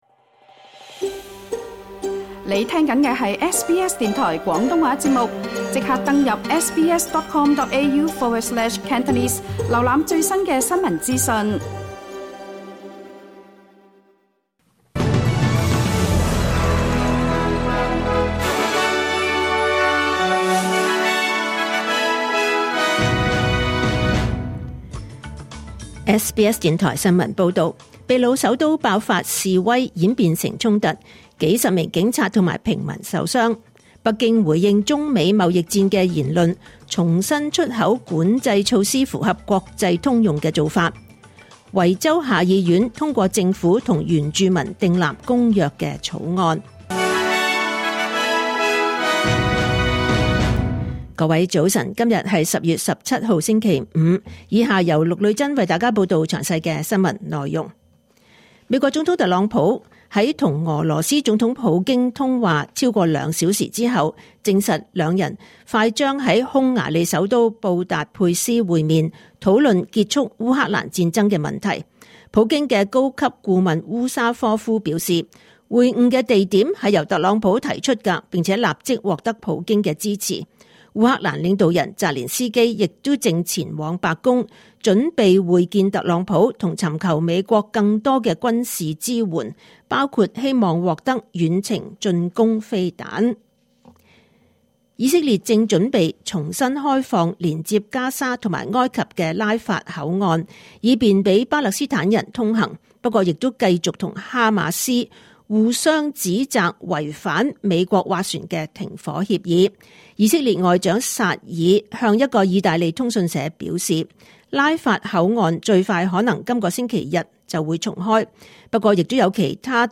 2025年10月17日SBS廣東話節目九點半新聞報道。